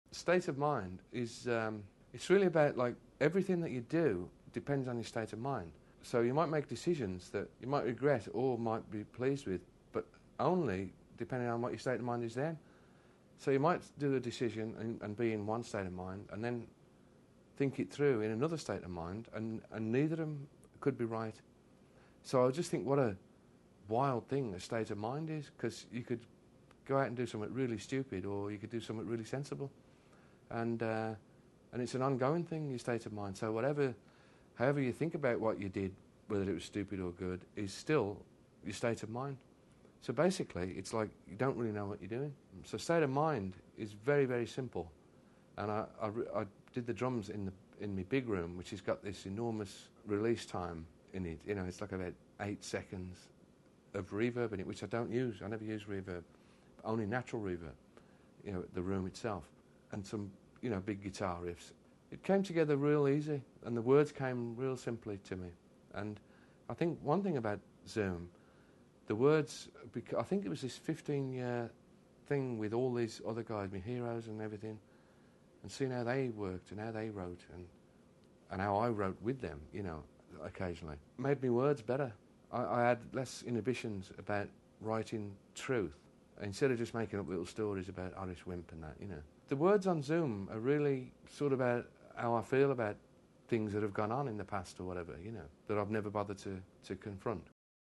ELO Interview Disc